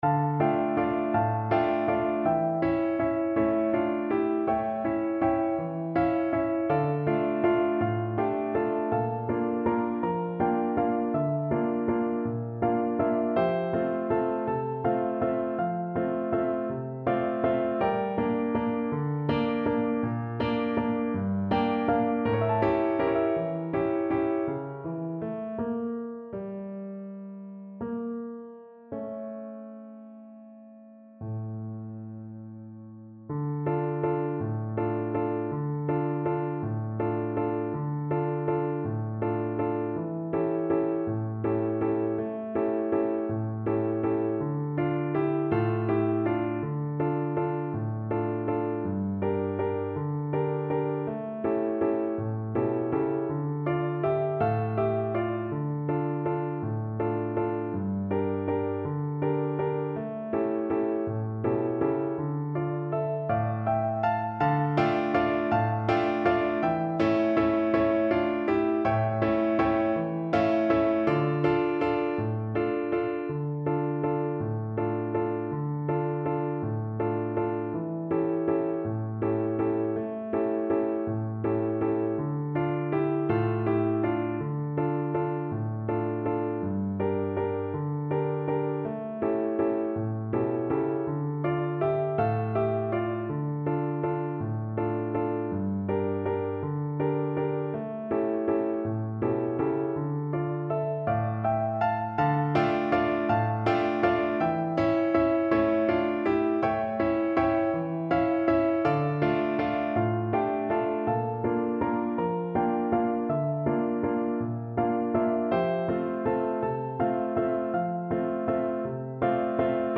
Flute version
One in a bar .=c.54
3/4 (View more 3/4 Music)
Flute  (View more Easy Flute Music)
Classical (View more Classical Flute Music)
Ukrainian